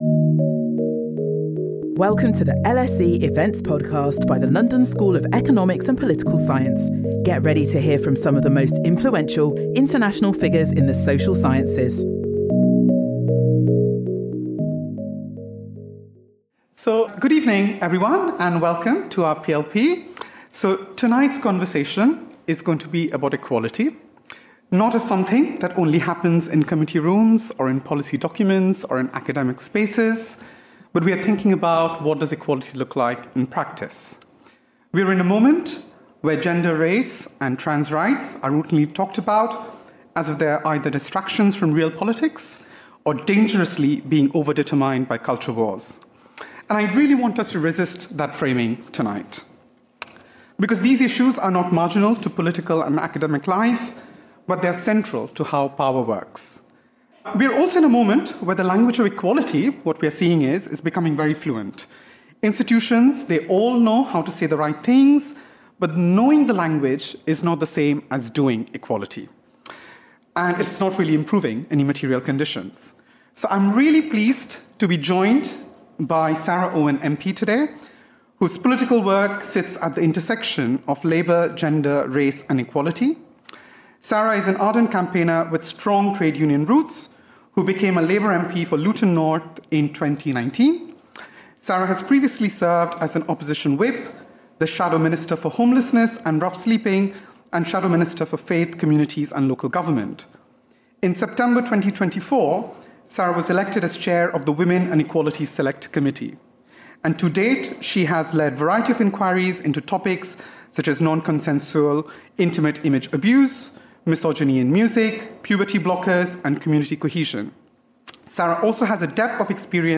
Join us for a timely conversation on equality, gender and culture in contemporary Britain with Sarah Owen MP, chair of the Women and Equalities Select Committee.
LSE: Public lectures and events